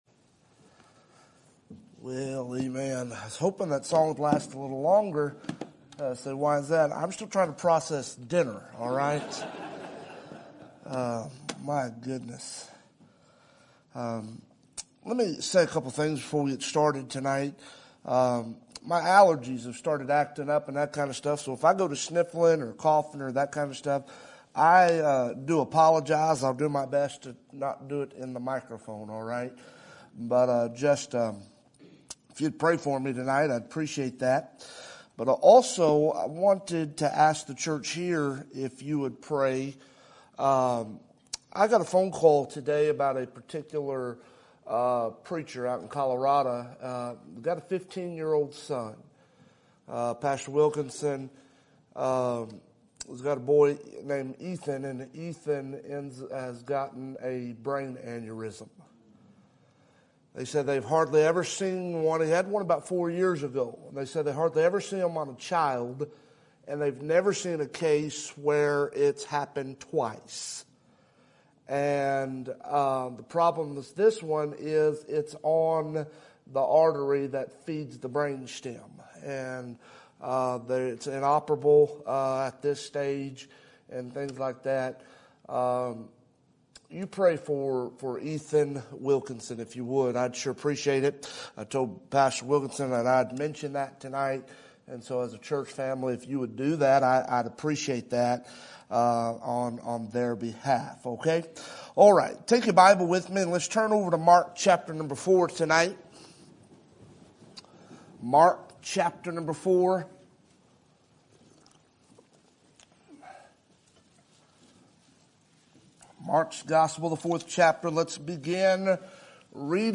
Sermon Date
Sermon Topic: Spring Revival Sermon Type: Special Sermon Audio: Sermon download: Download (21.02 MB) Sermon Tags: Mark Revival storm Jesus